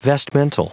Prononciation du mot vestmental en anglais (fichier audio)